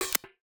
UIClick_Denied Negative Mechanical Hollow 03.wav